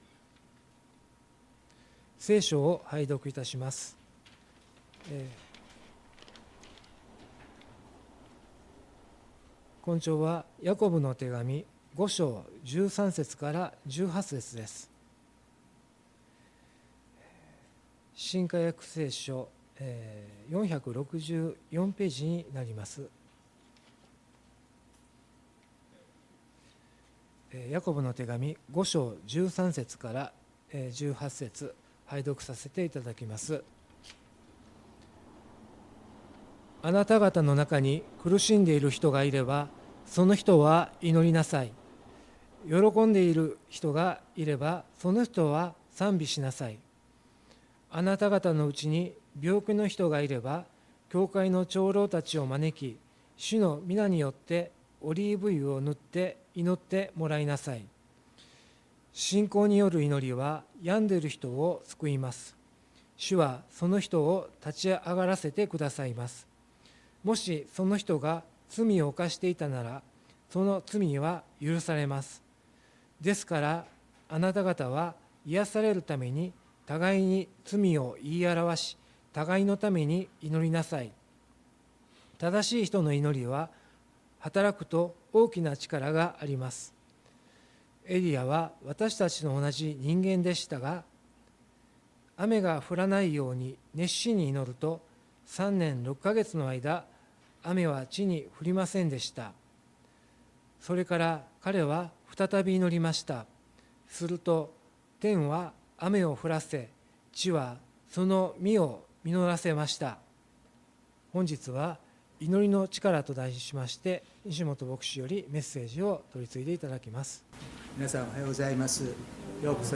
礼拝メッセージ「祈りの力」│日本イエス・キリスト教団 柏 原 教 会